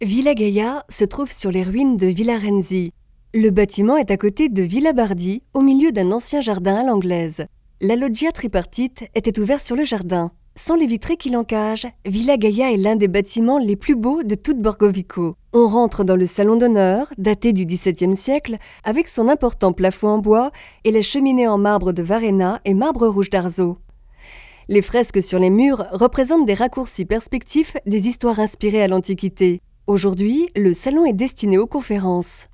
Voix off en Français
Commerciale, Urbaine, Distinctive, Polyvalente, Amicale
Guide audio